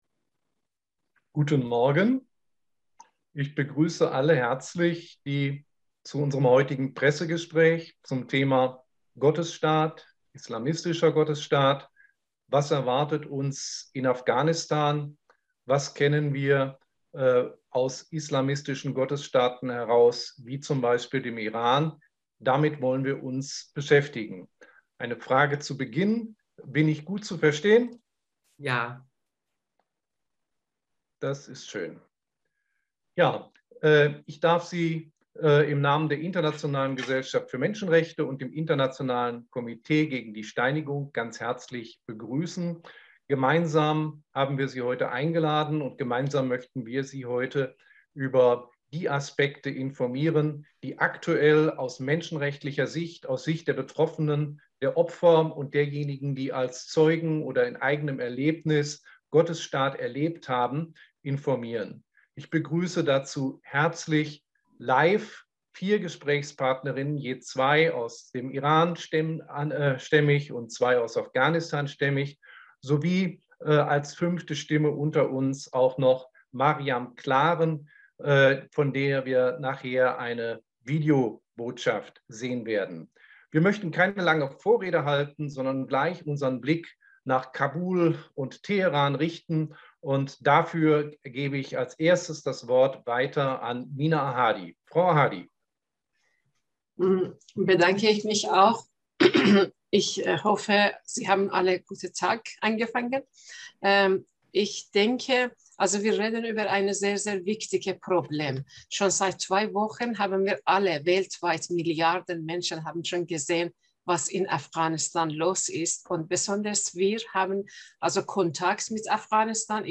Pressegespraech-Audio.aac